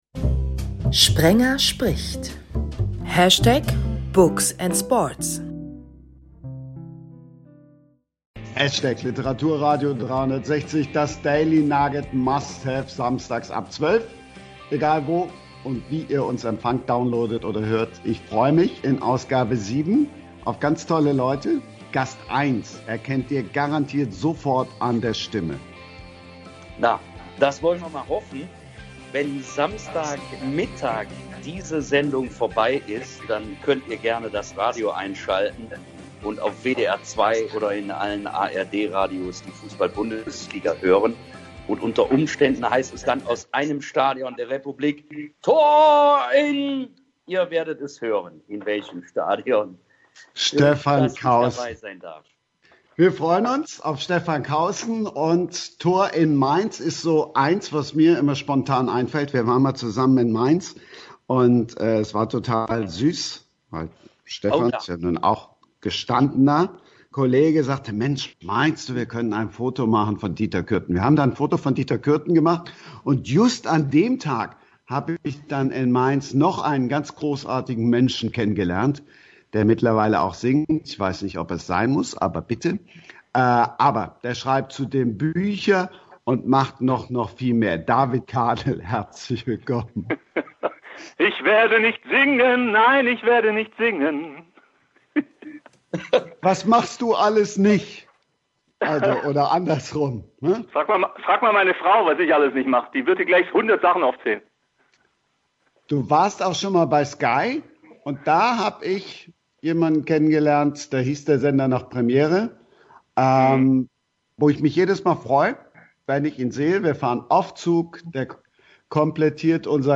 Deshalb auch etwas länger die Diskussion. Aber: gelacht wird trotzdem und ganz groß geschrieben wird Empathie.